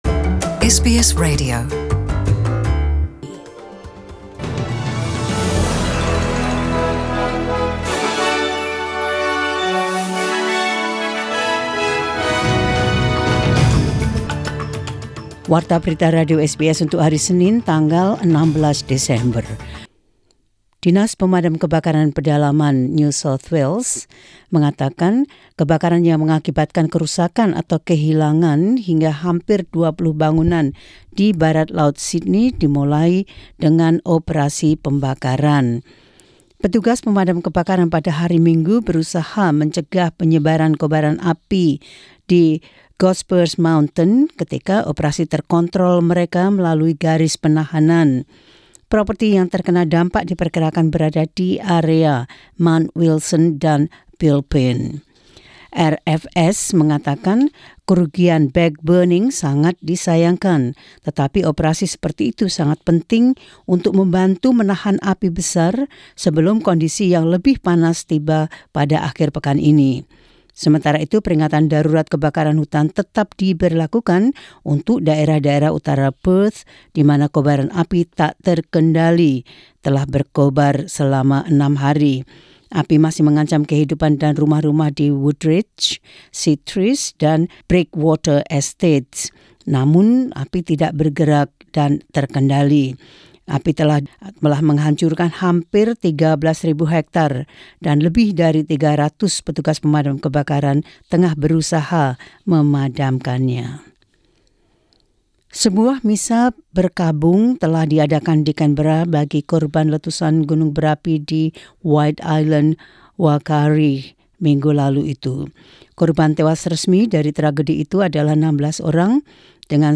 SBS Radio News in Indonesian 16 Dec 2019.